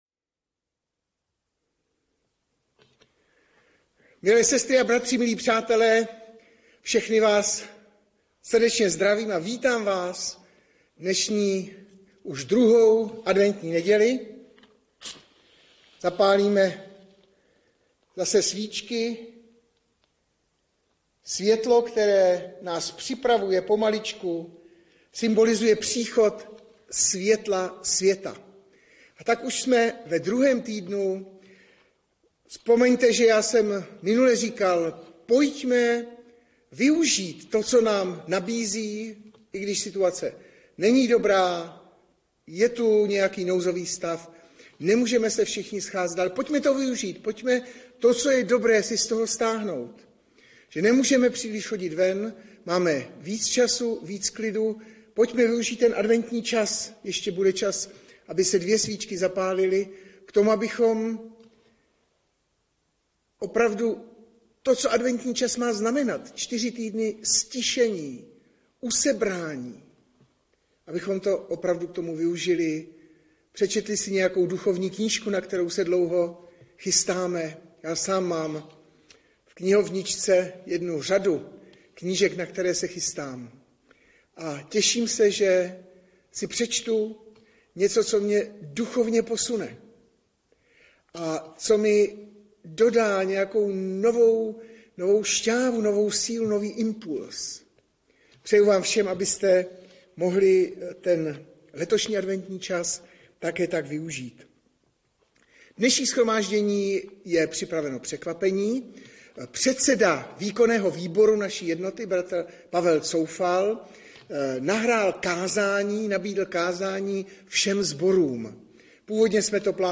Audiozáznam kázání si můžete také uložit do PC na tomto odkazu.